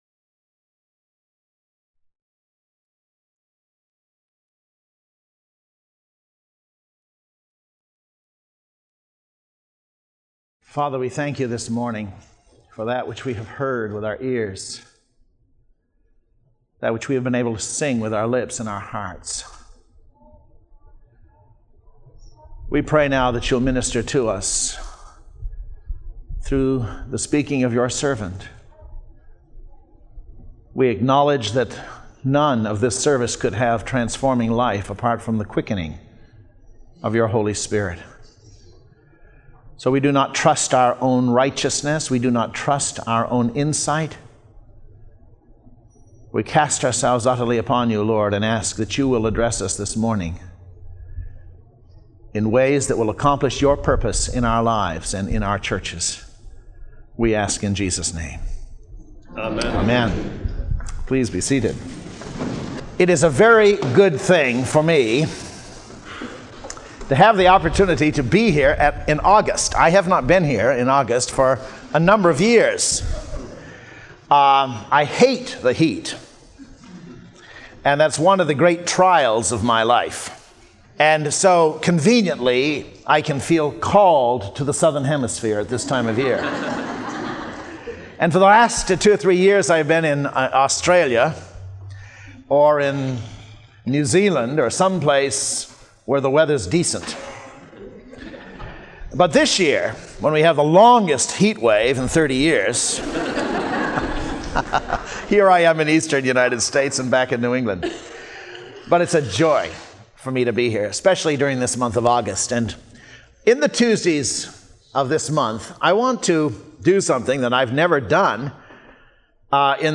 We highly recommend these sermons by various speakers.